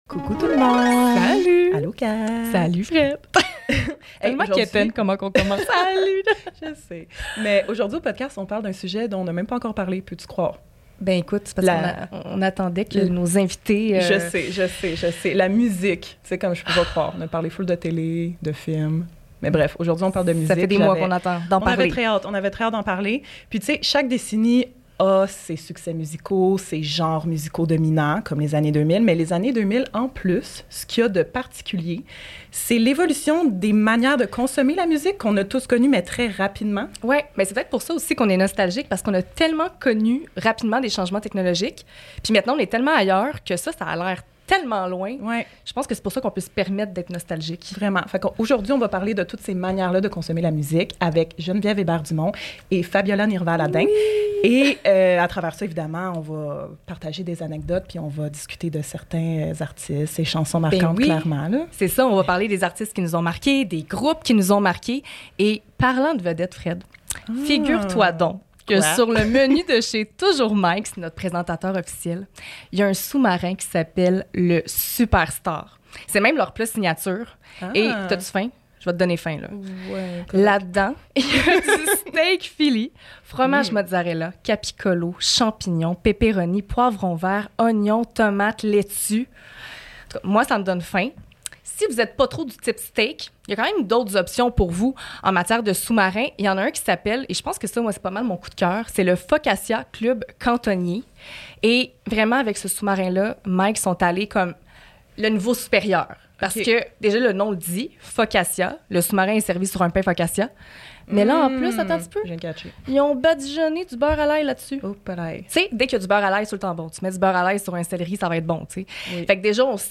On chante beaucoup!